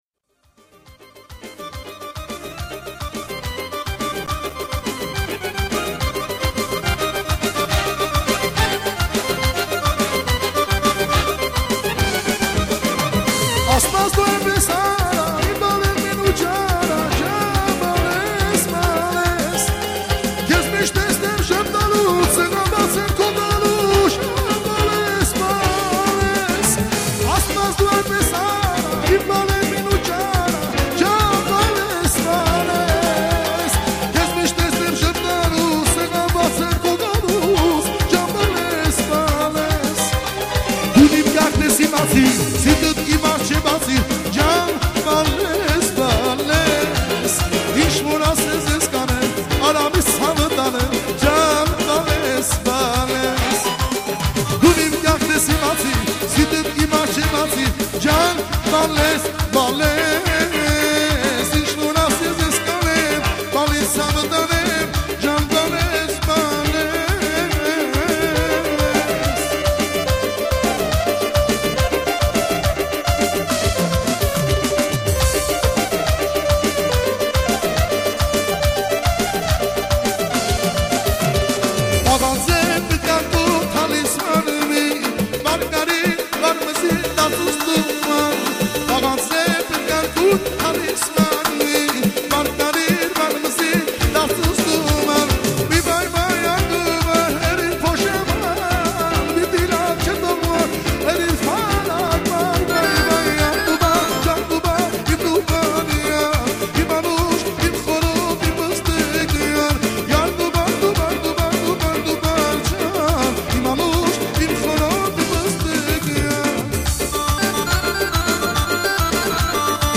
շարան